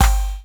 Index of /90_sSampleCDs/Classic_Chicago_House/Drum kits/kit02
cch_09_perc_clave_high_punch_multi.wav